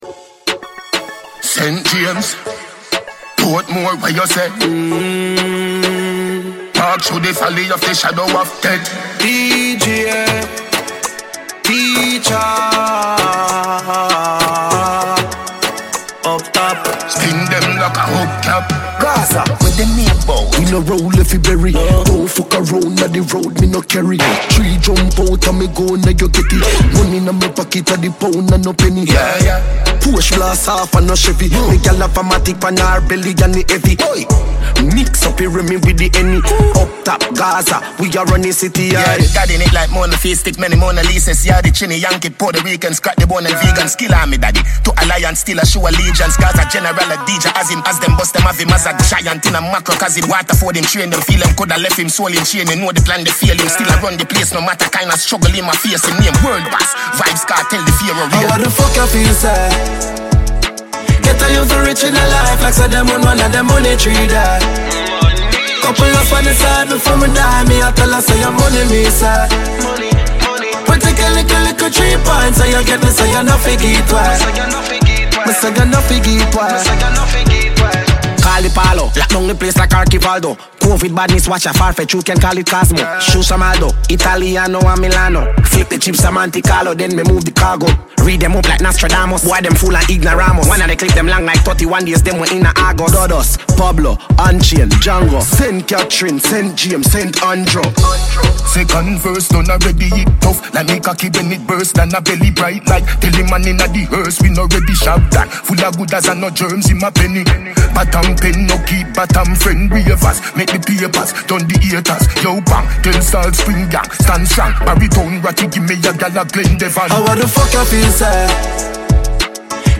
dancehall song